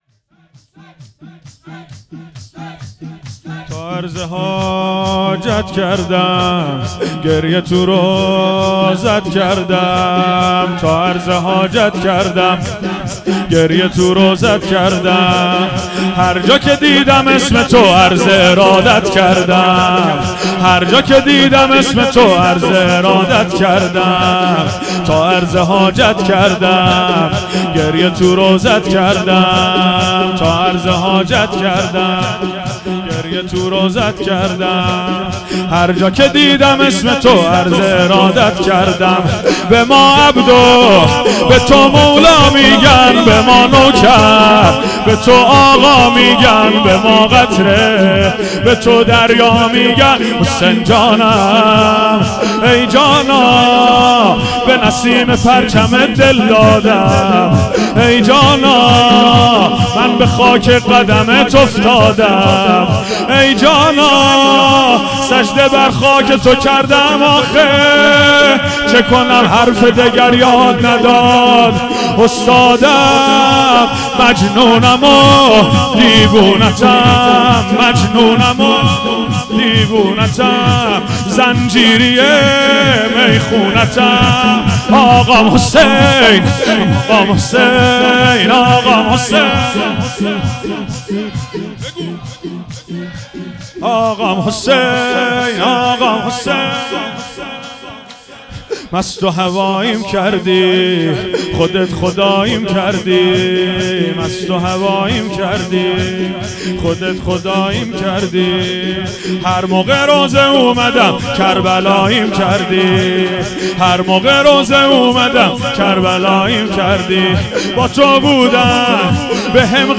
هیئت حسن جان(ع) اهواز - شور
دهه اول محرم الحرام ۱۴۴۴